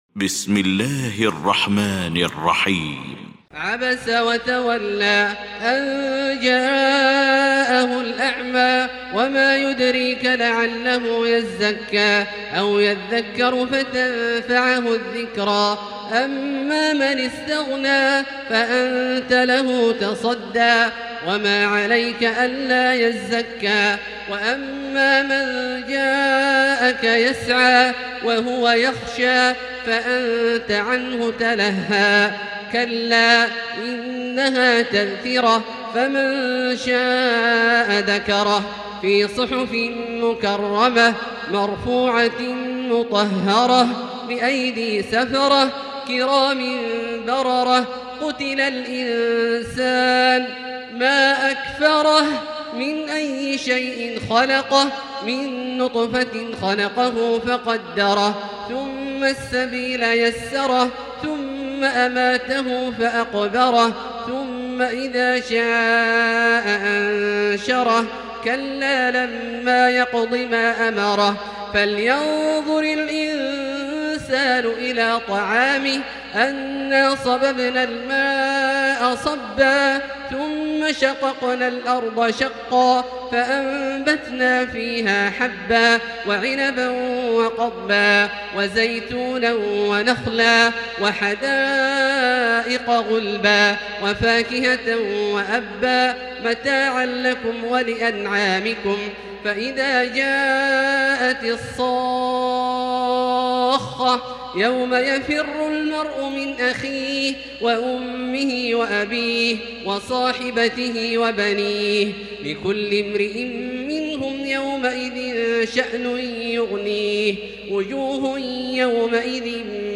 المكان: المسجد الحرام الشيخ: فضيلة الشيخ عبدالله الجهني فضيلة الشيخ عبدالله الجهني عبس